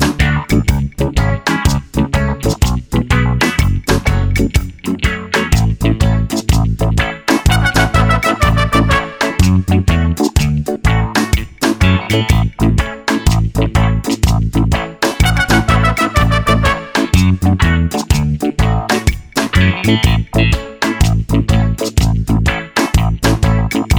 no Backing Vocals Reggae 4:28 Buy £1.50